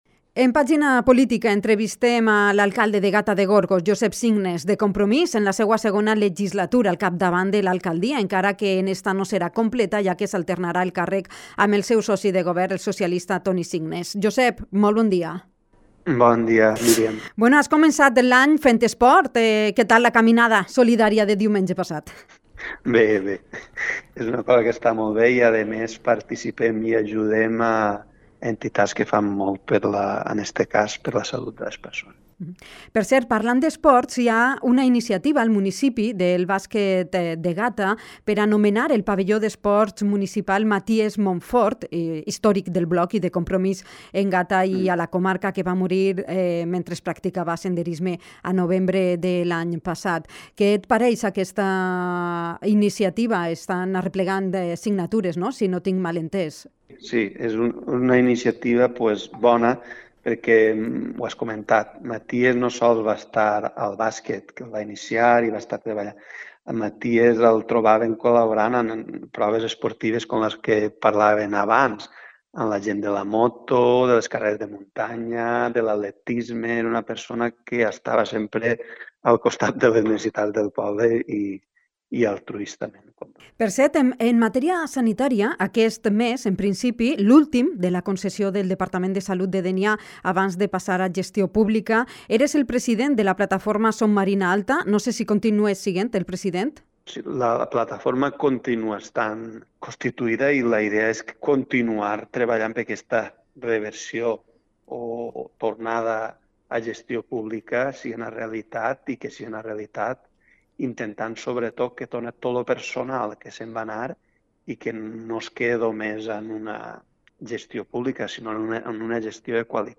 Entre esos proyectos que ha comentado para los micrófonos de Dénia FM, en una entrevista telefónica, «me gustaría acabar la implantación definitiva de la recogida puerta a puerta«, así como «seguir invirtiendo en la instalación de fotovoltaicas para conseguir un autoconsumo real».
Entrevista-alcalde-Gata.mp3